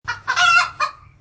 chickenHit.mp3